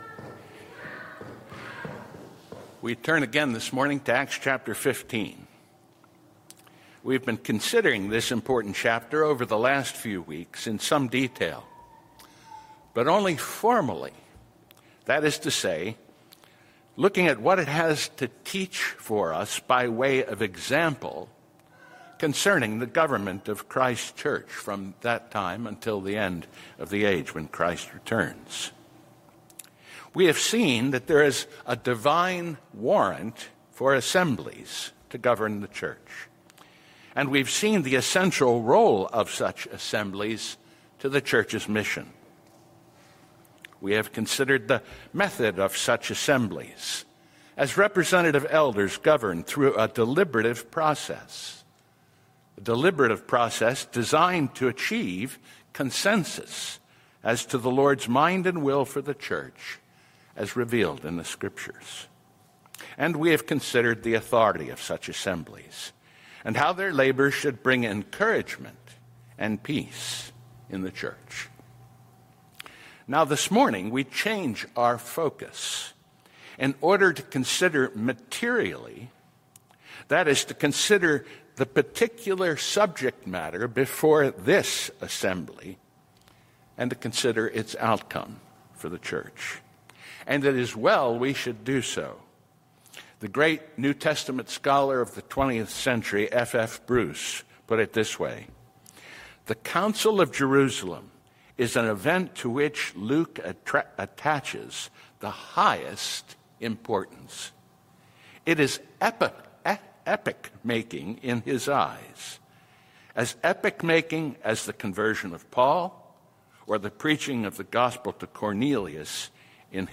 The Church Gathered from Among the Nations: Sermon on Acts 15:6-21 - New Hope Presbyterian Church